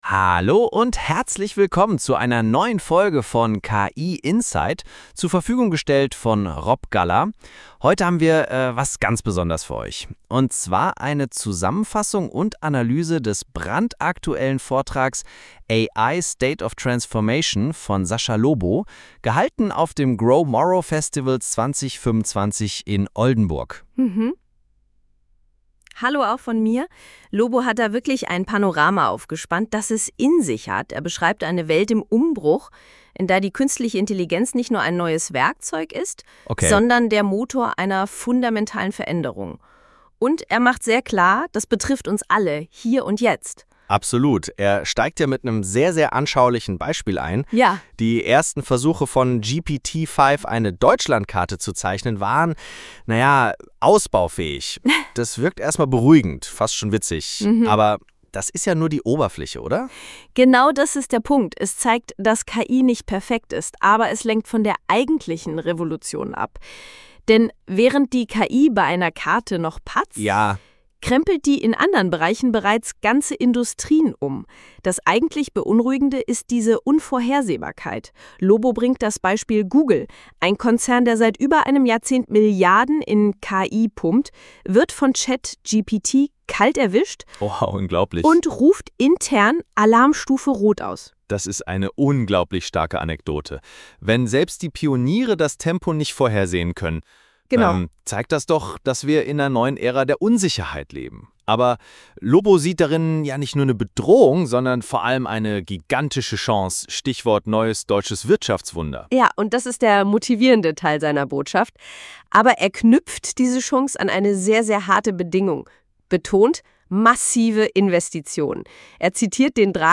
100 % KI erzeugter Podcast